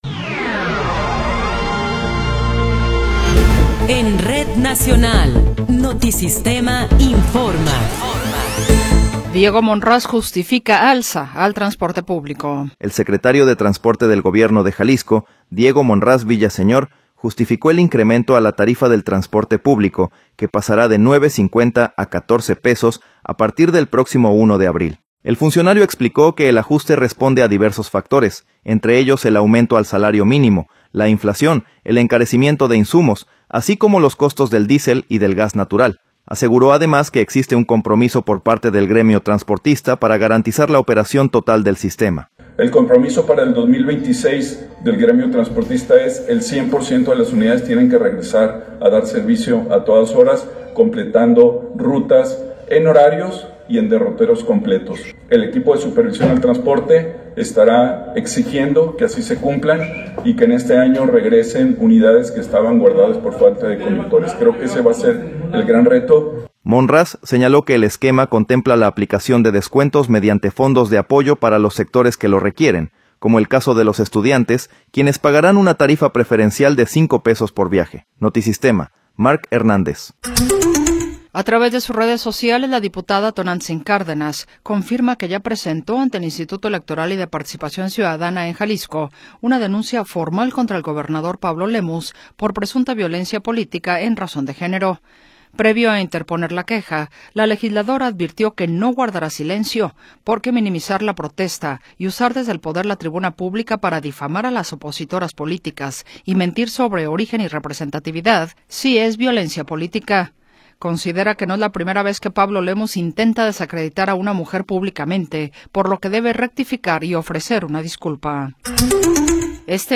Noticiero 17 hrs. – 22 de Enero de 2026